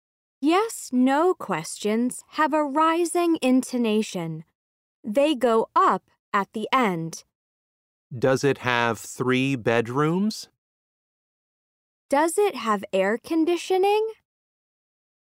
SO1- Unit 4- Lesson 3 (Intonation).mp3